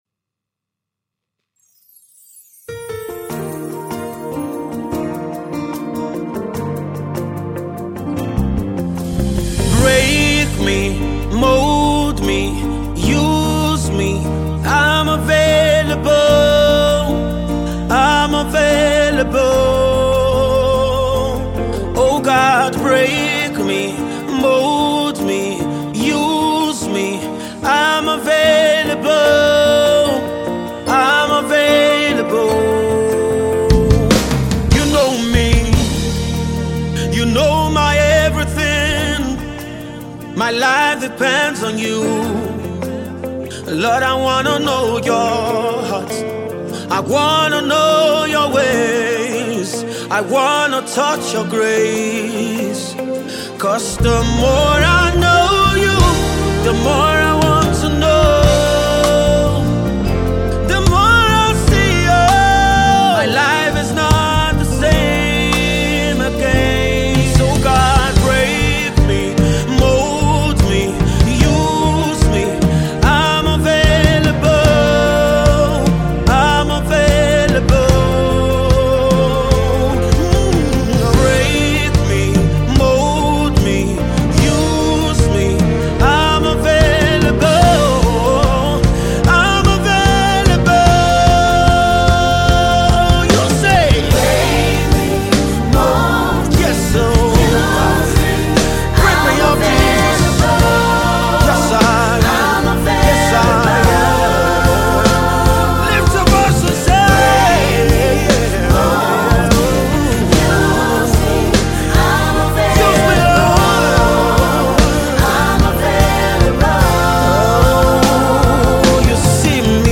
new spirit filled song to uplift our spirit